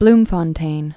(blmfŏn-tān)